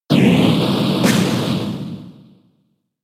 whirlwind-2.mp3